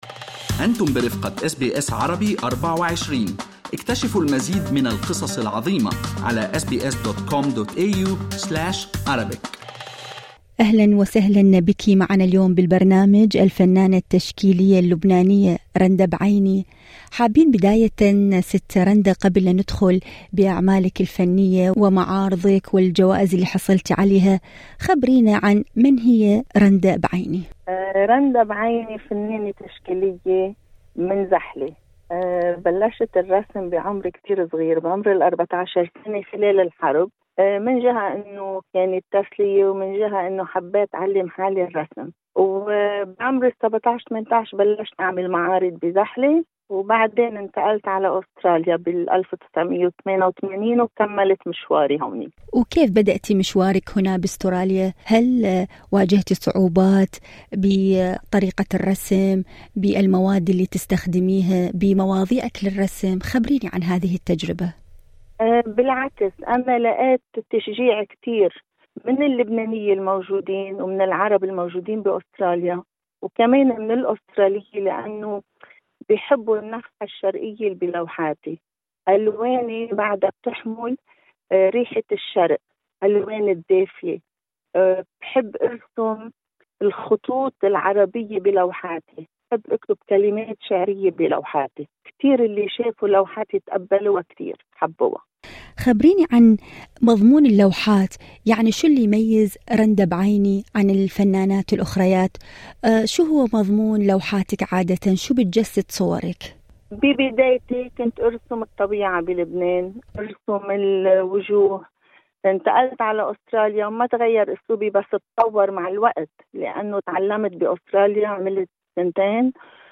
المزيد في المقابلة الصوتية اعلاه استمعوا لبرنامج "أستراليا اليوم" من الاثنين إلى الجمعة من الساعة الثالثة بعد الظهر إلى السادسة مساءً بتوقيت الساحل الشرقي لأستراليا عبر الراديو الرقمي وتطبيق Radio SBS المتاح مجاناً على أبل وأندرويد.